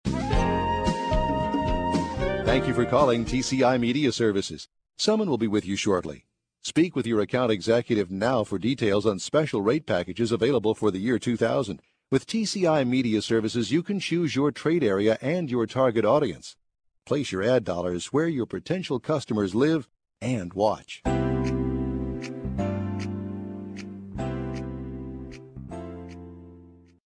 Audio Demos - Message On Hold